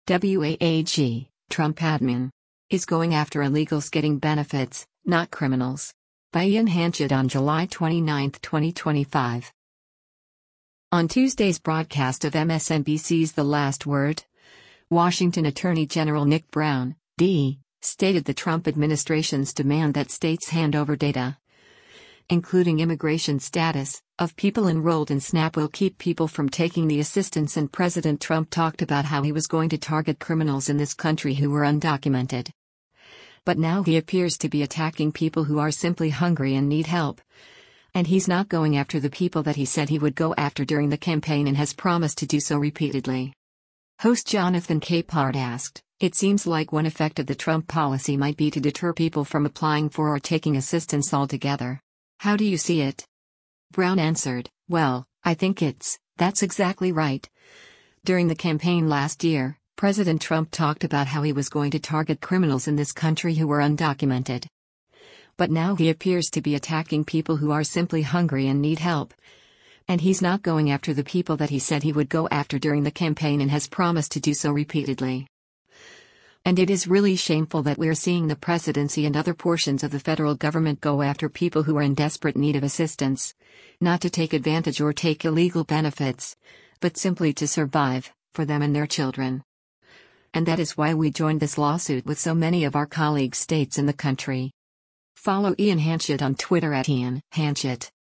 Host Jonathan Capehart asked, “It seems like one effect of the Trump policy might be to deter people from applying for or taking assistance altogether. How do you see it?”